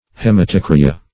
Search Result for " hematocrya" : The Collaborative International Dictionary of English v.0.48: Hematocrya \Hem`a*toc"ry*a\, n. pl.